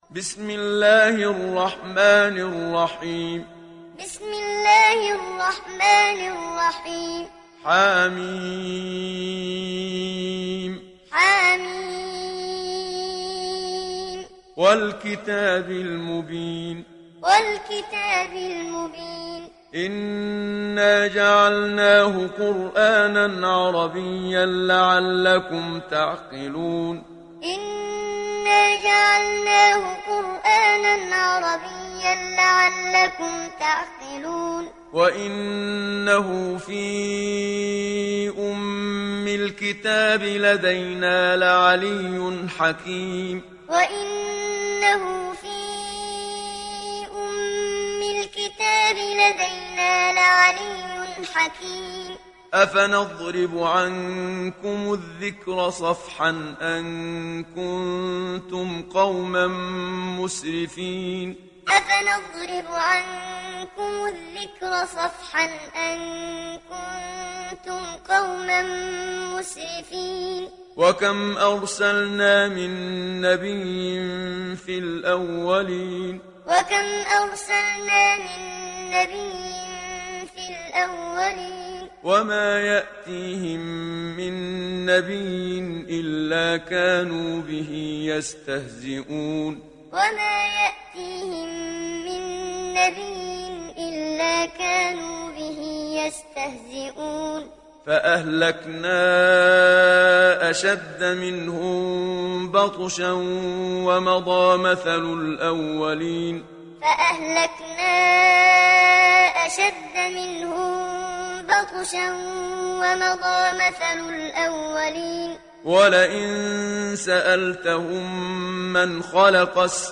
Zuhruf Suresi mp3 İndir Muhammad Siddiq Minshawi Muallim (Riwayat Hafs)